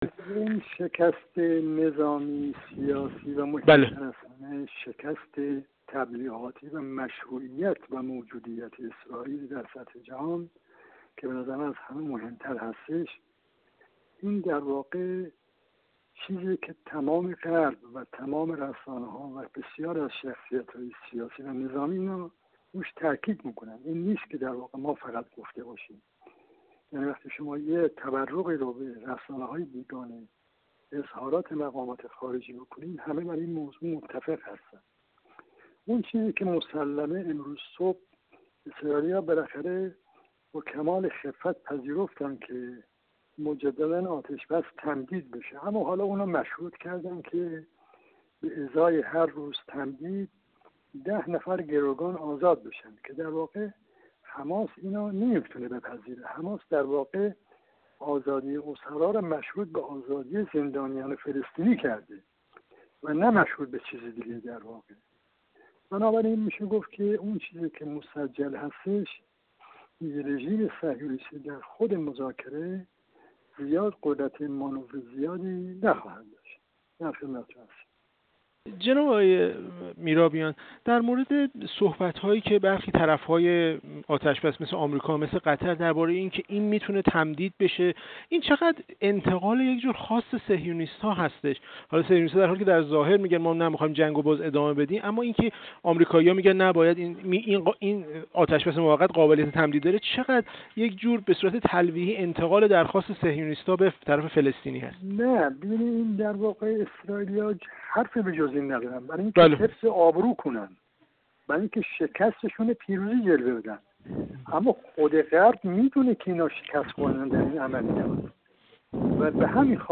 سیدرضا میرابیان، سفیر اسبق جمهوری اسلامی ایران در کویت
گفت‌وگو